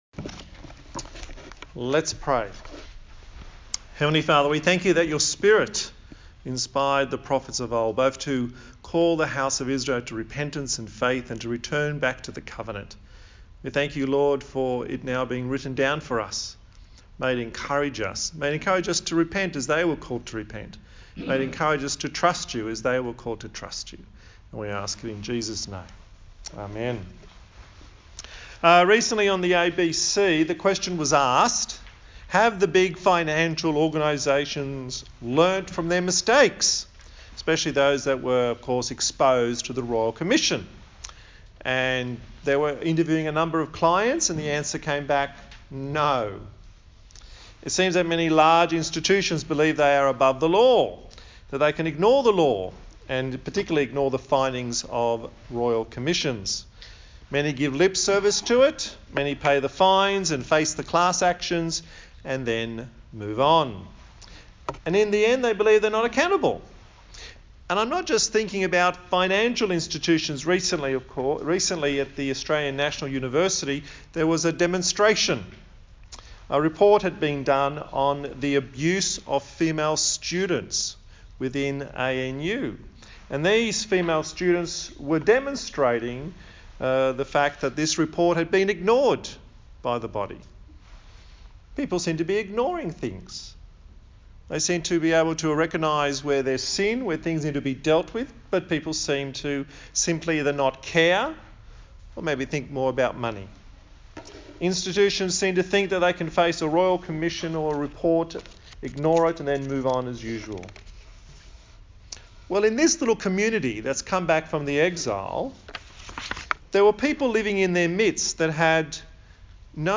18/08/2019 The Removal of Wickedness Preacher